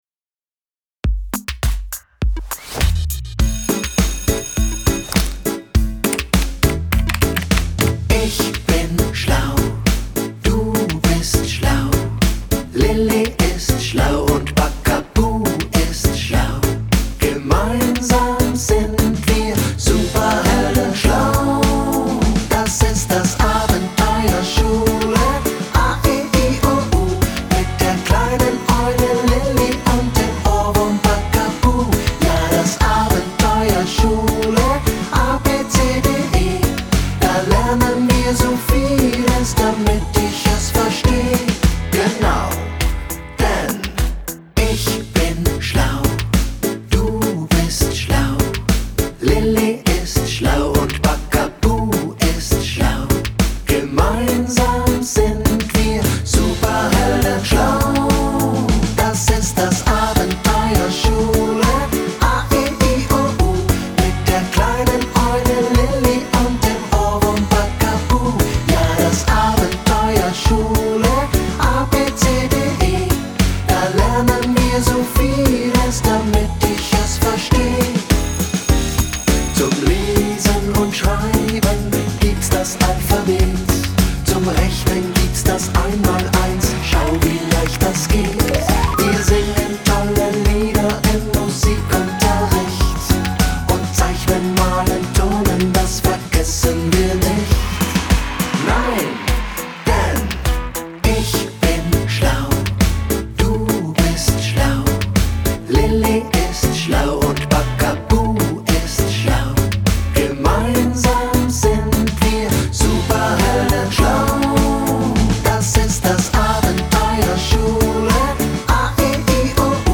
Lieder singen
Musik zum Anhören und Mitsingen.